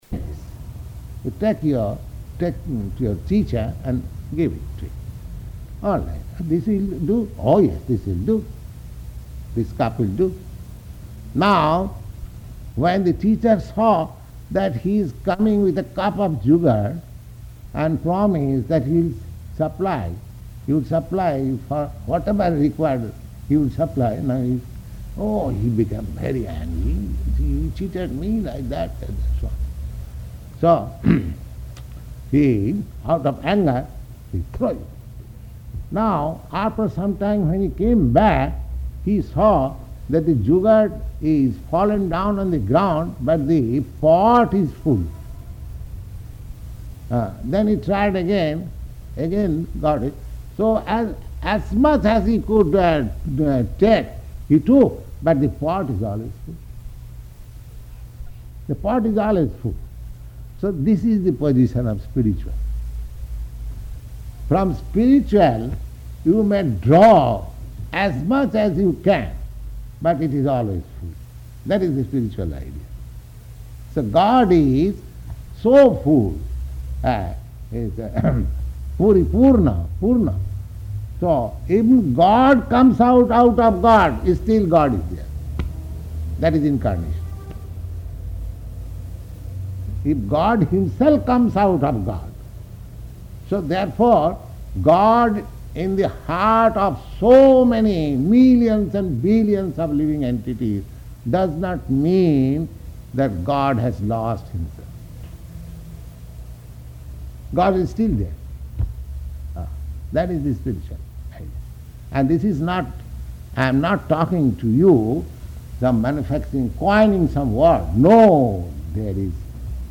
Lecture
Location: New York